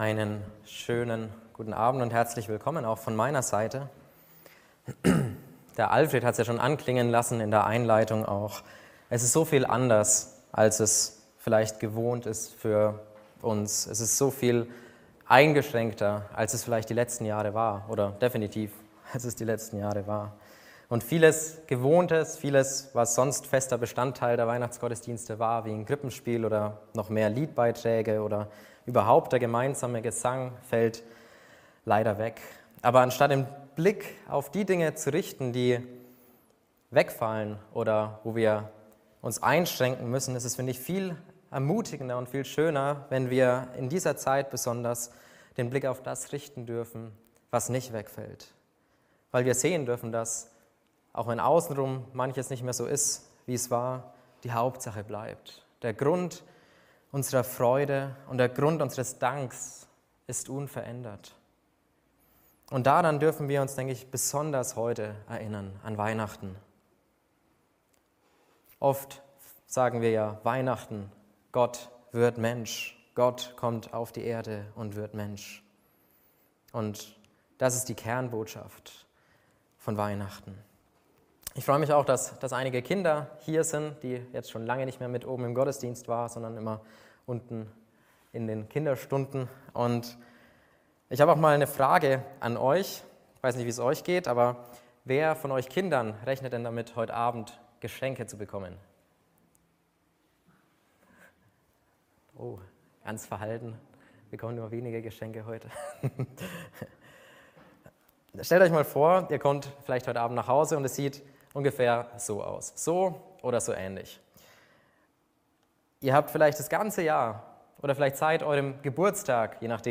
Die Unterpunkte der Predigt waren Gott – über uns – gegen uns – mit uns Getaggt mit Gott mit uns , Weihnachten Aufnahme (MP3) 19 MB Zurück Habt Geduld - Jesus ist im Kommen Weiter Jahreslosung 2021